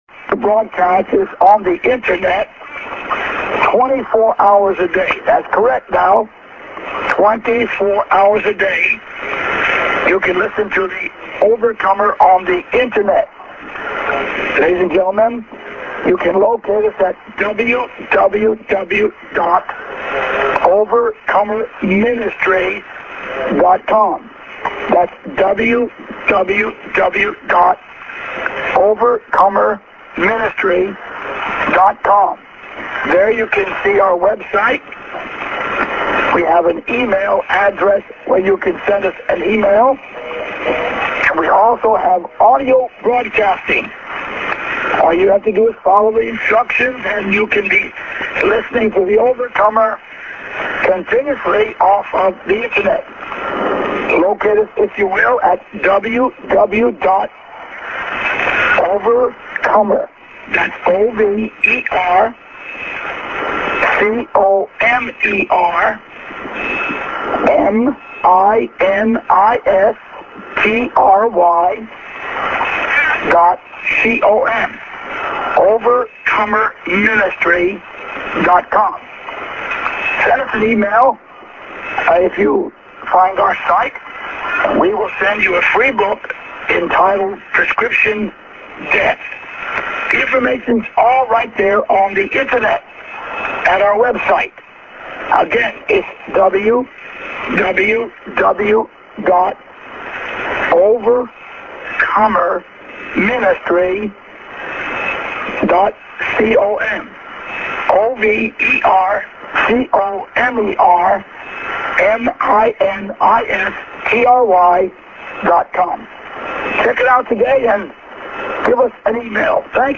St. ID+Web ADDR(man)->SJ->